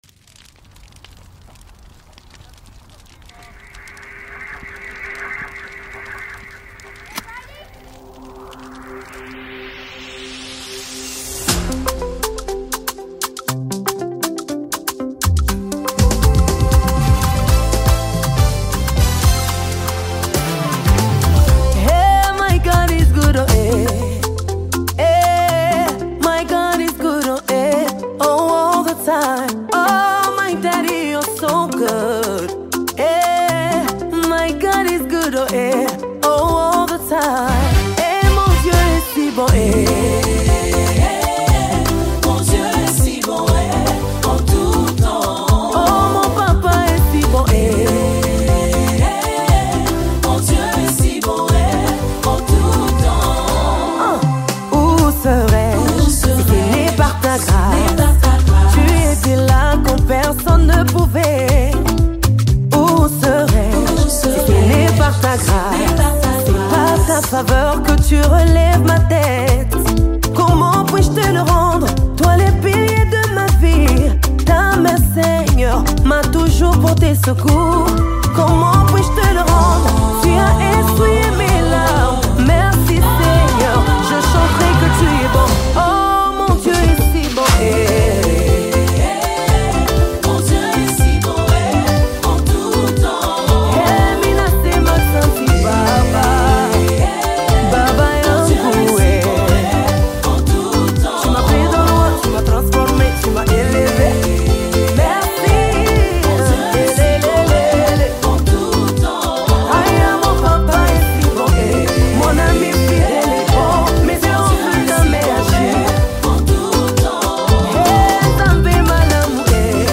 United States Gospel recording artist
worship song
Through heartfelt lyrics and soulful melodies